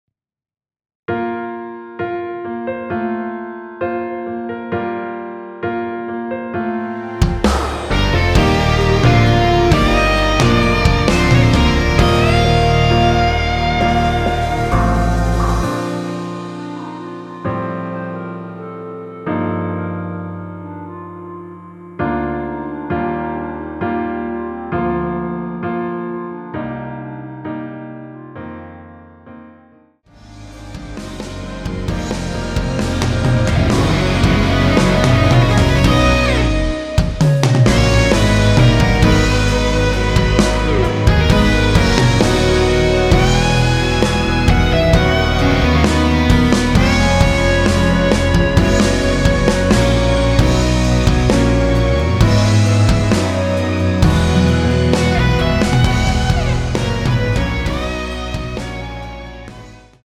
원키에서(-3)내린 멜로디 포함된 MR입니다.(미리듣기 확인)
Bb
앞부분30초, 뒷부분30초씩 편집해서 올려 드리고 있습니다.
중간에 음이 끈어지고 다시 나오는 이유는